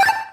Techmino/media/effect/chiptune/reach.ogg at beff0c9d991e89c7ce3d02b5f99a879a052d4d3e